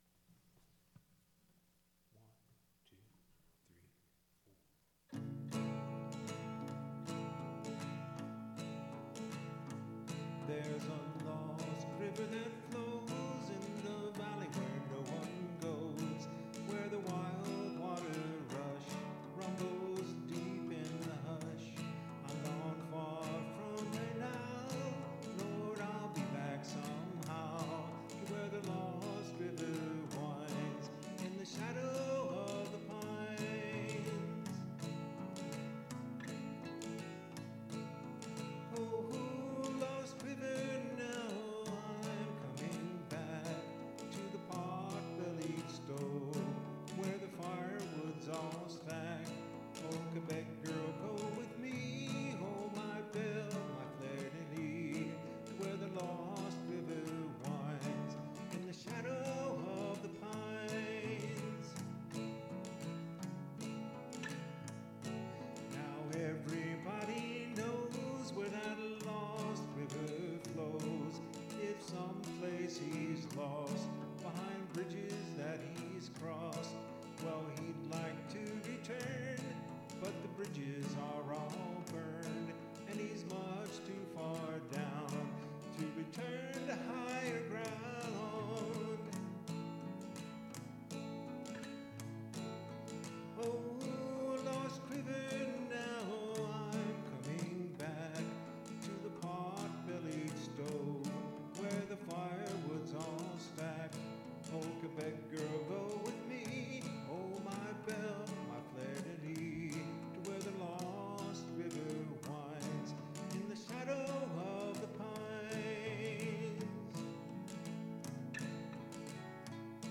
MP3 Copy of Studio Recording
Guitar
Bass Guitar
Vocal